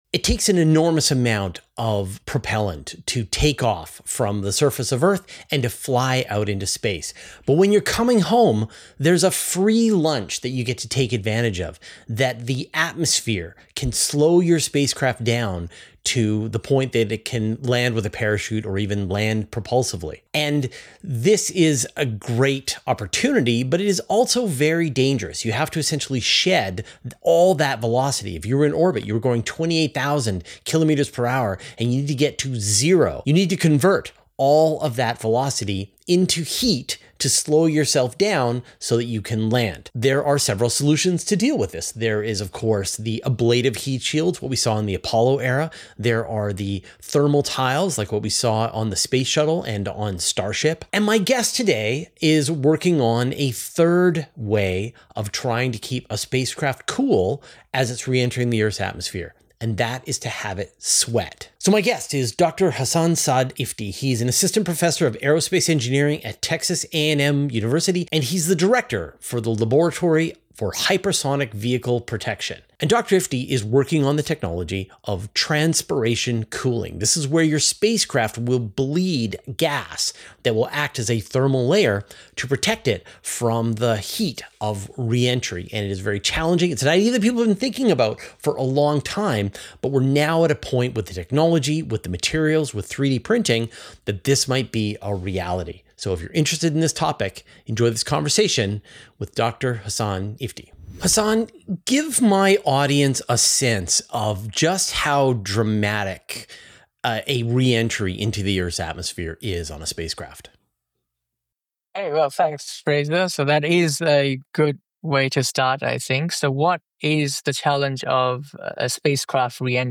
[Interview] Can Sweating Heat Shields Solve Re-Entry Problems for Reusable Rockets?